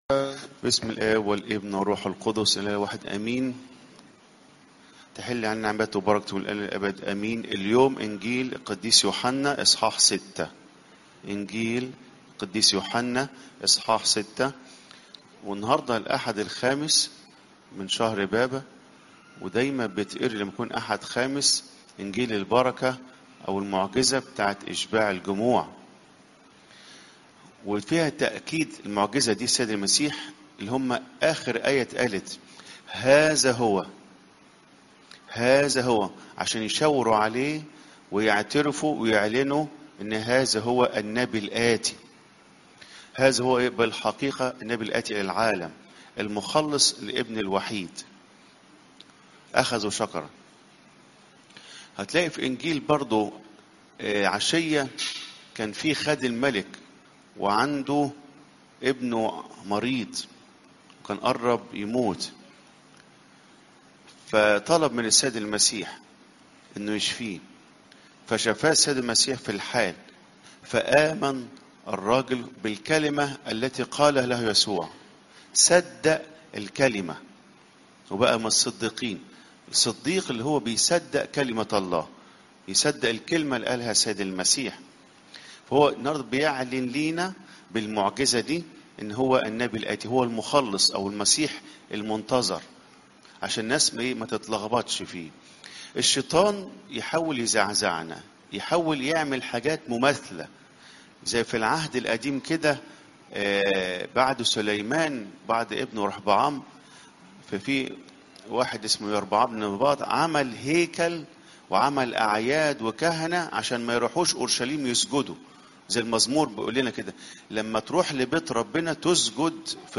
عظات قداسات الكنيسة (يو 6 : 5 - 14) الاحد الخامس من شهر بابة